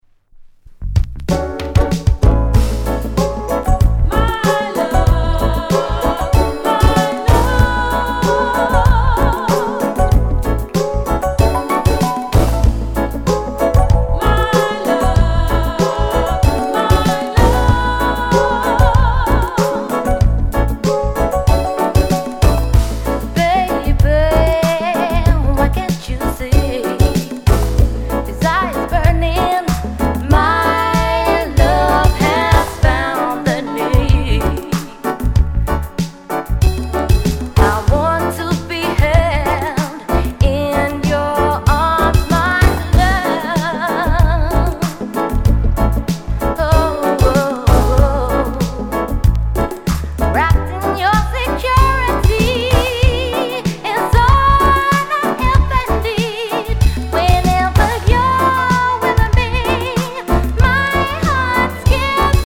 RARE LOVERS ROCK A cover song